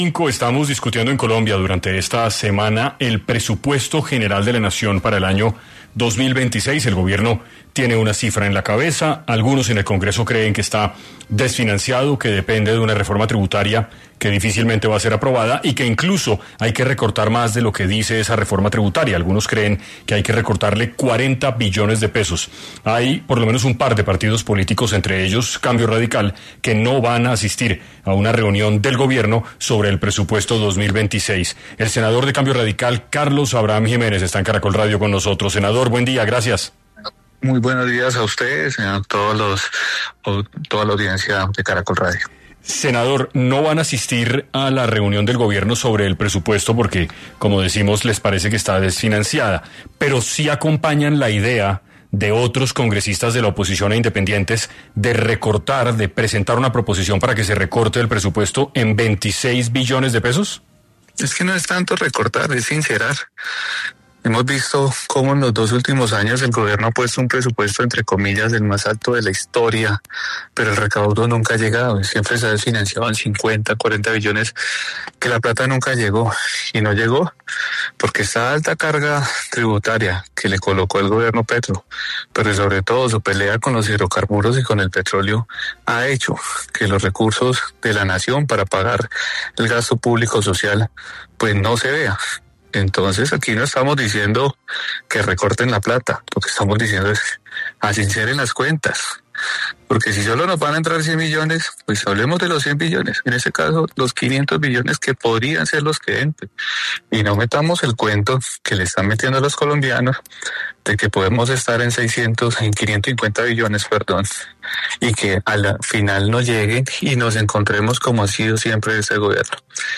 En Caracol Radio estuvo el senador Carlos Abraham Jiménez explicando los motivos por los que Cambio Radical no se sentará con Petro en la Casa de Nariño para discutir el presupuesto del 2026